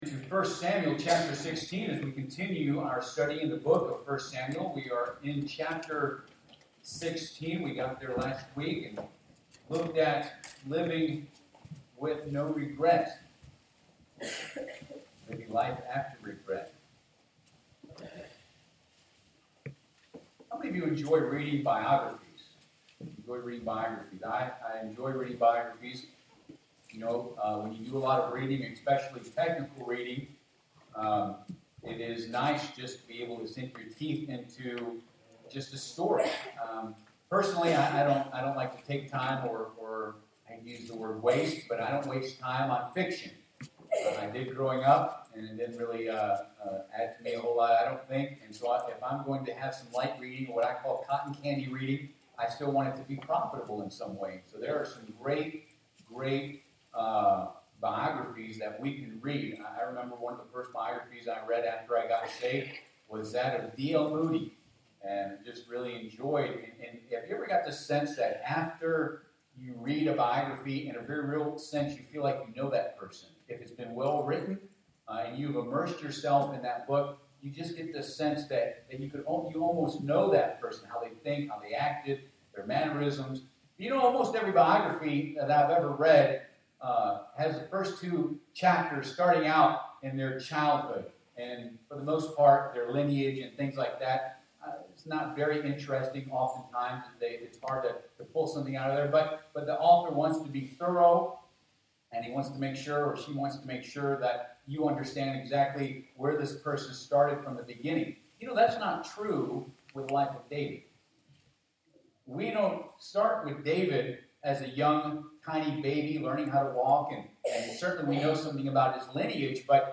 Passage: 1 Samuel 16 Service Type: Sunday PM Bible Text